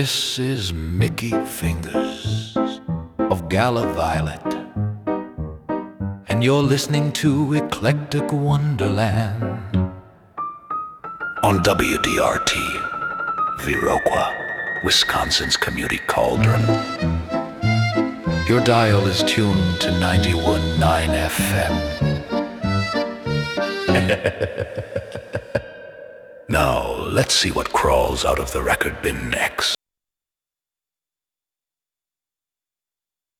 is a graveyard glam feast for the shadow-dwellers.